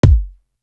Grand Entry Kick.wav